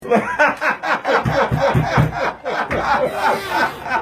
bolsonaro-nao-se-aguenta-e-morre-de-ri-da-maria-do-rosario-massoqueisso.mp3